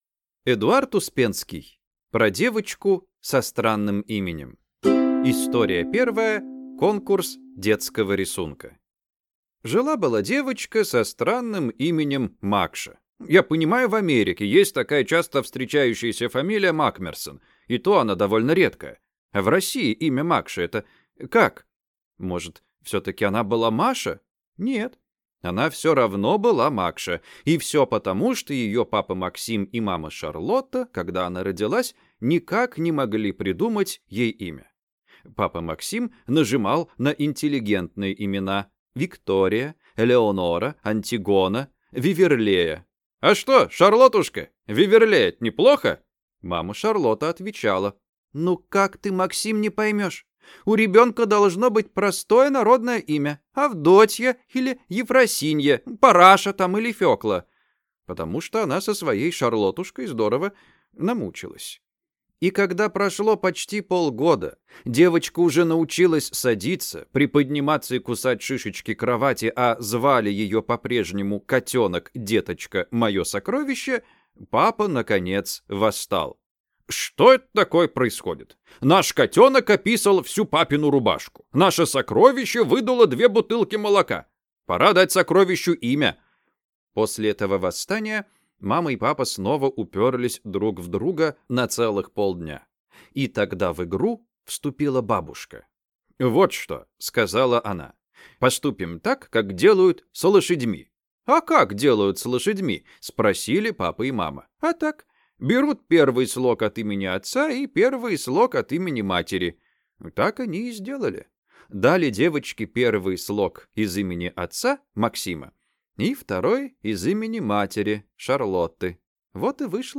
Аудиокнига Про девочку со странным именем | Библиотека аудиокниг